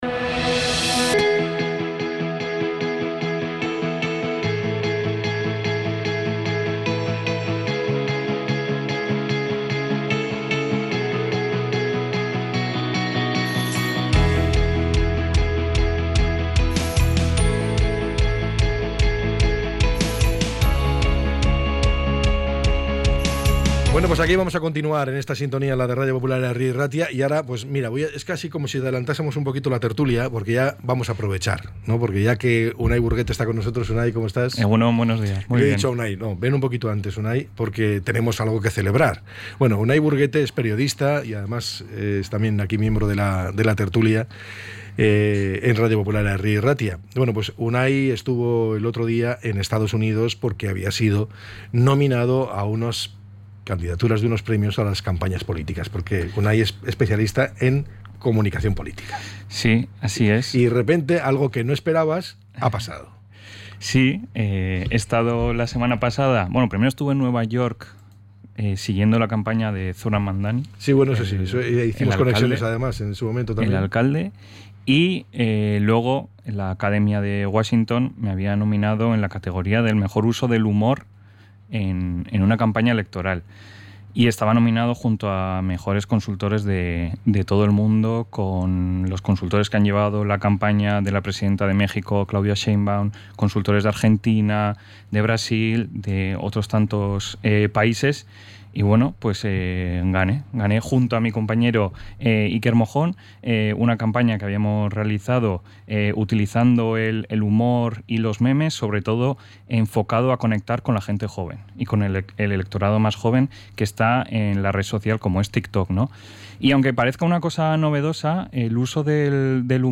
Podcast Política